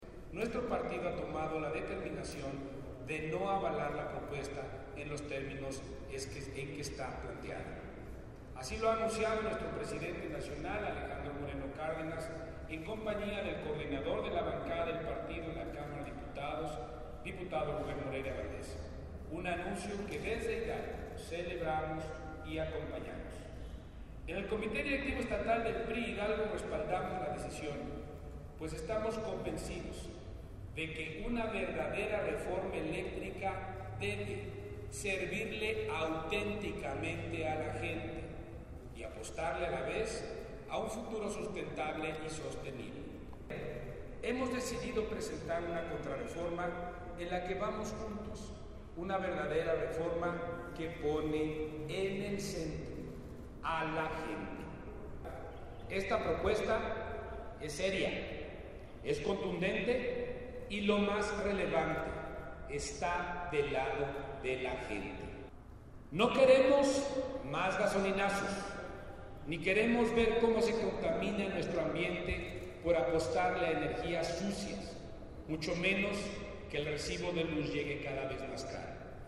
convocó a rueda de prensa, la cual tuvo lugar en la sede del CDE, con la finalidad de fijar postura en torno a la Iniciativa de la Reforma Energética que impulsa el presidente de la República.